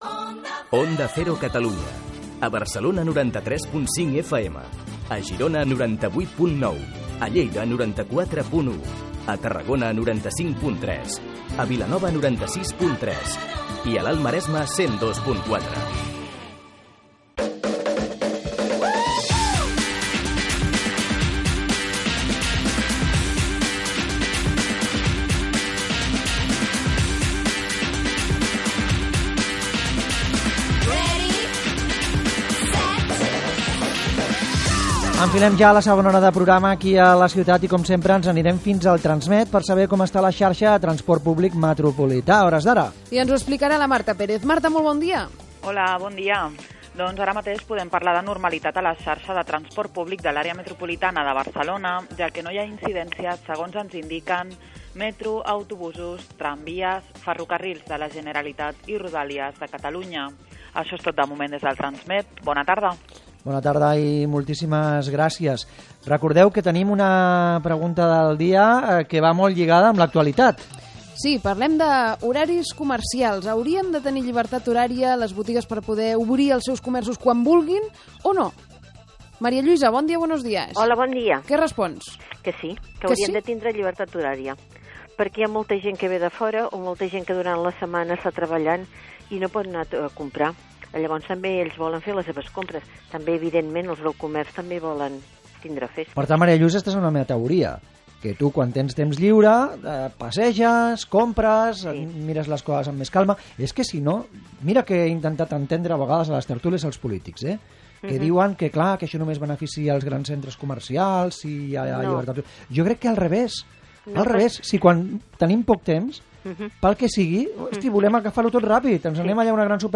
Radio: Podcast Programa «La Ciutat». La Pareja Ideal. 15 Diciembre 2014